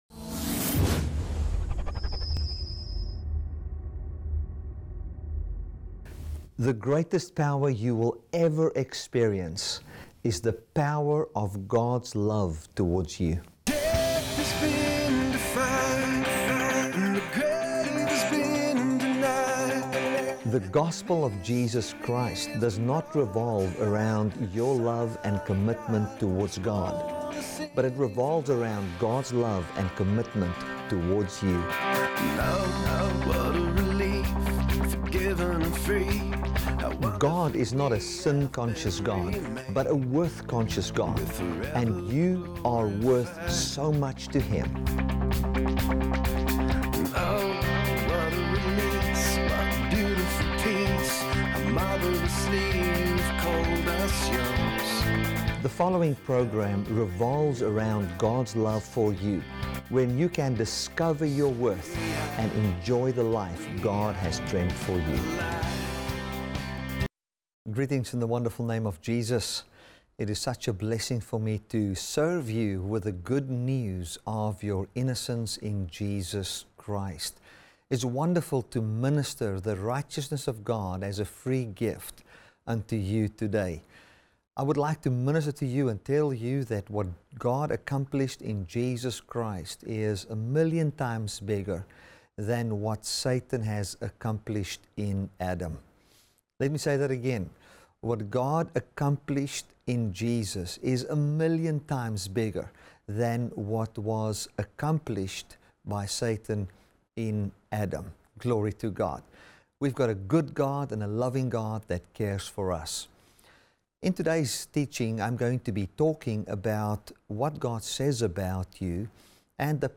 April 05, 2017 | TV BROADCASTING | TBN